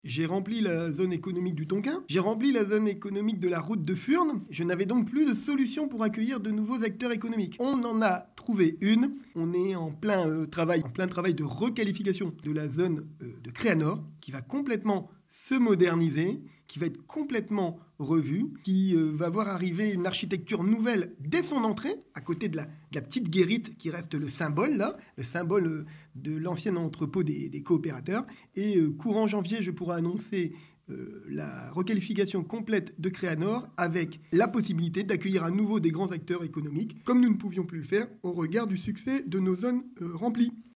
David Bailleul, le maire de Coudekerque-Branche.